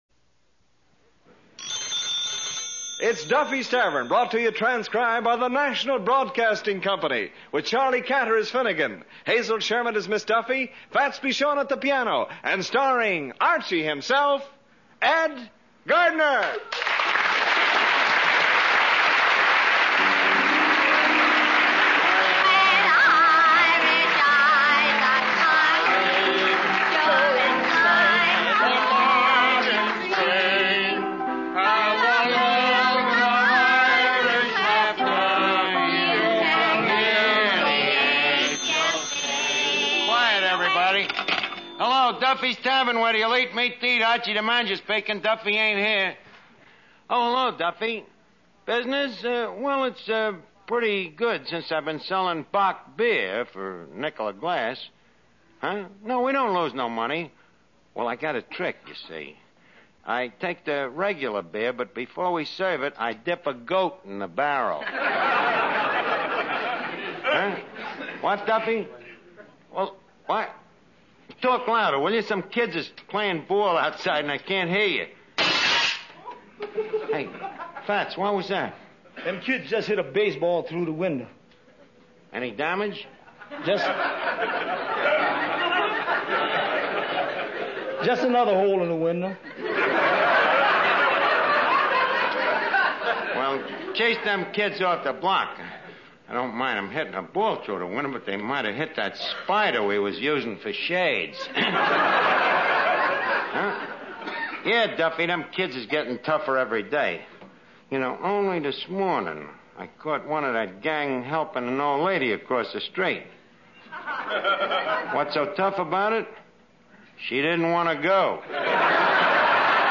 Duffy's Tavern Radio Program, Starring Ed Gardner